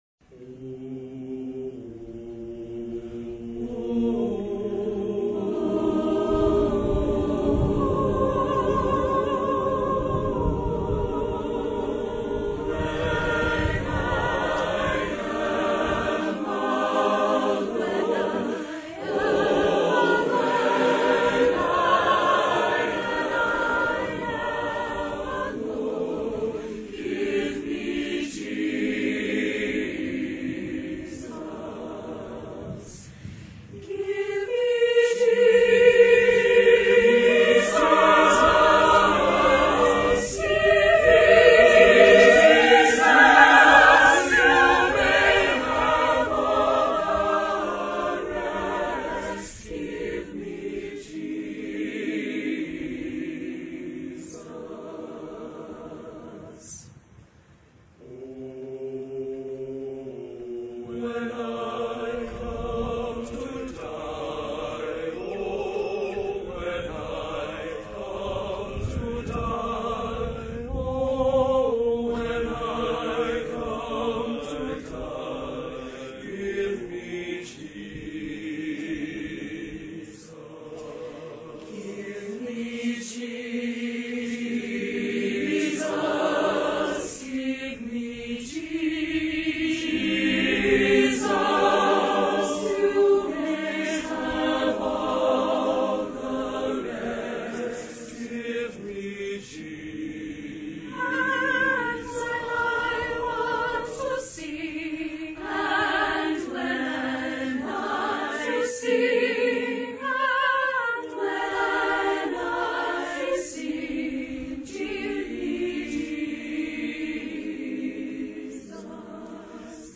Spring 2004 Commencement: Anthem
Performed by the Union University Singers